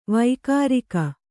♪ vaikārika